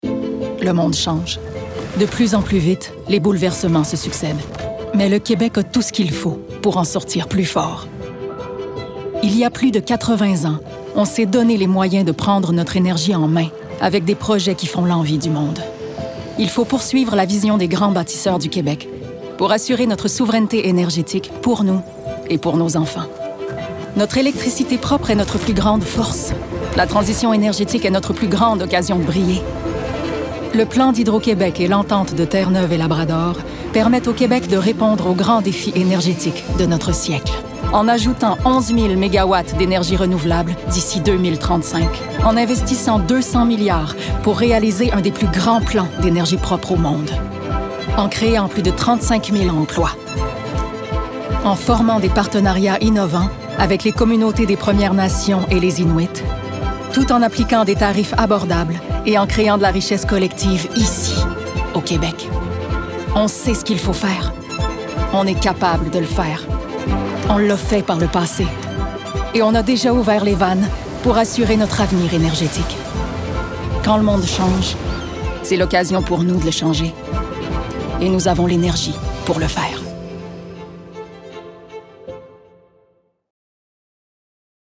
voices
Publicity - Voix Person./Ann 6lo